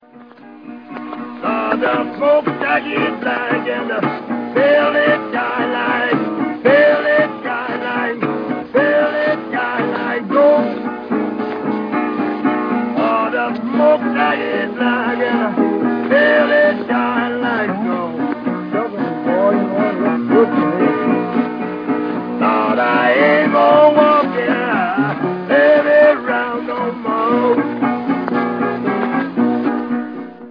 партия второй гитары